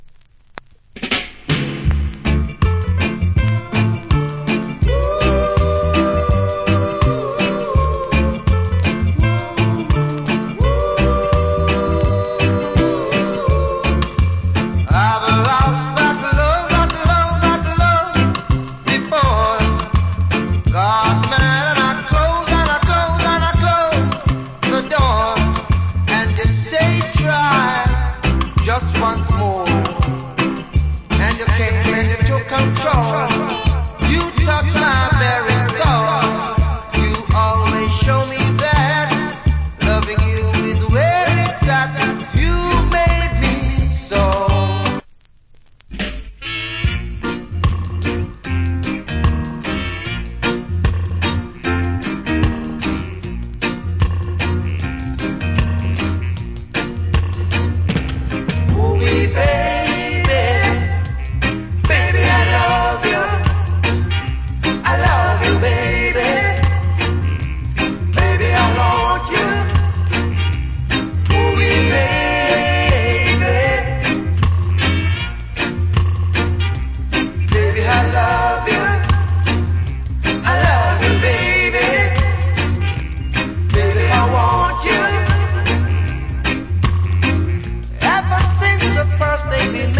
Nice Reggae Vocal + DJ. Self Cover Song.